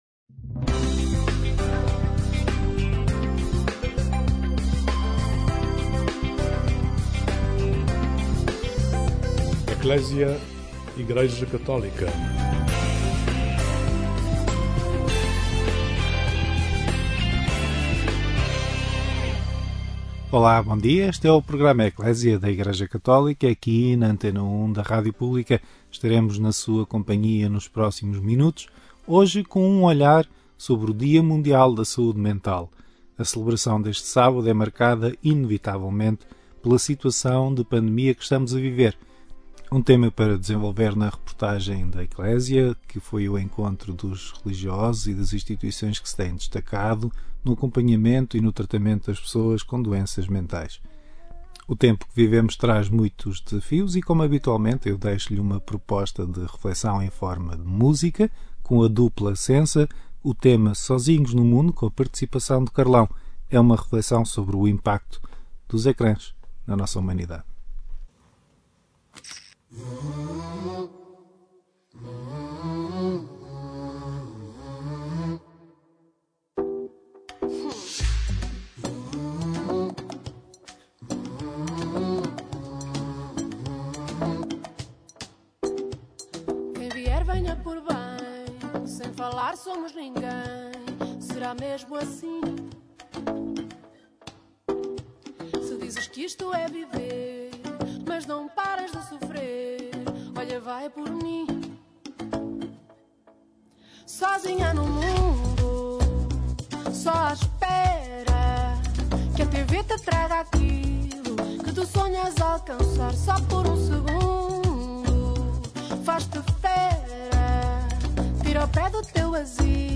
A respeito do Dia Mundial da Saúde Mental, um “parente pobre” dos investimentos no setor, o programa ECCLESIA conversa com especialistas e religiosos que se dedicam ao cuidado destes doentes, num tempo de maior risco e desafios, devido à pandemia de Covid-19.